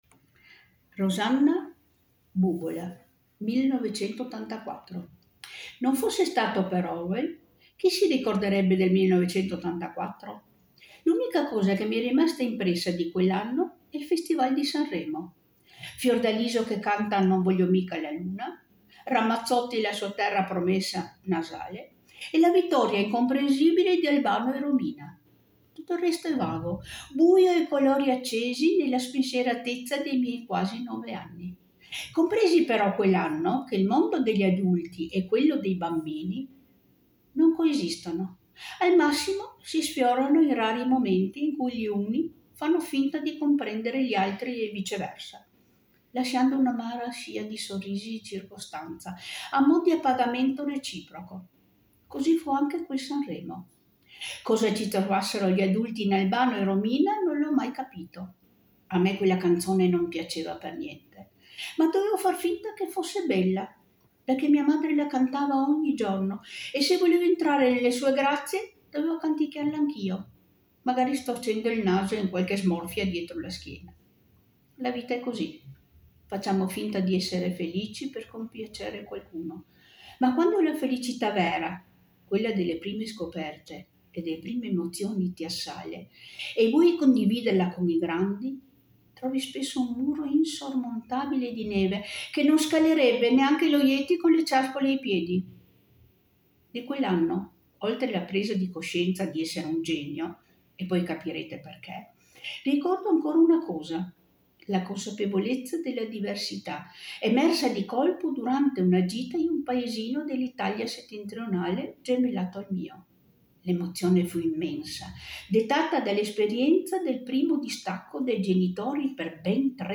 vi proponiamo altri assaggi dei testi del volume in uscita l’11 dicembre “Donne, frontiere, scritture” in versione audiolibro letti dalle Voci di Luna e L’altra.